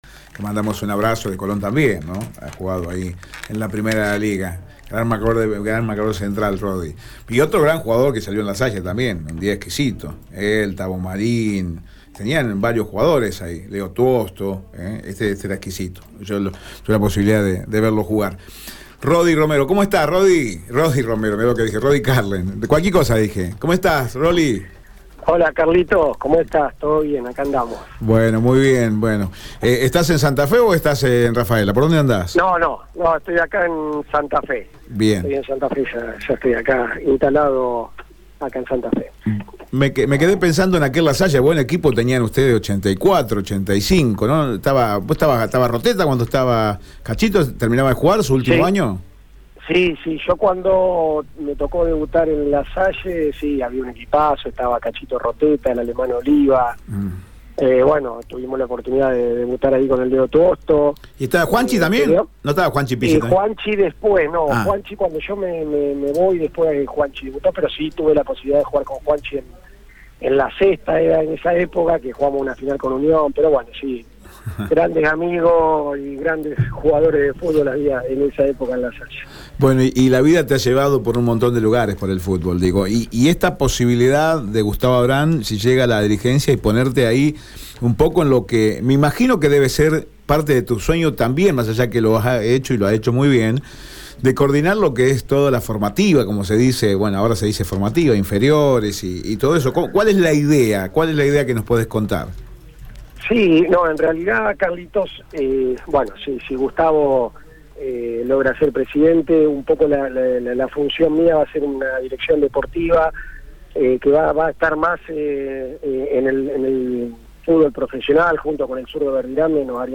dialogó con Radio EME sobre lo que espera de Colón en caso de ganar las elecciones.